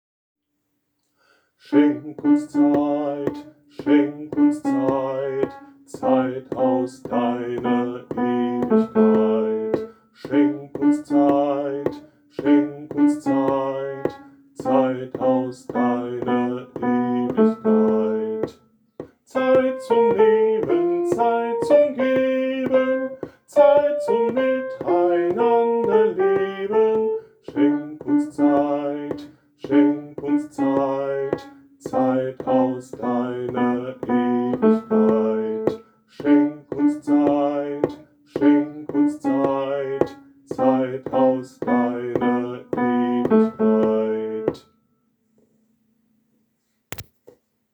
Evangelisch-lutherische Stadtkirchengemeinde Münden – Tondateien Morgenchor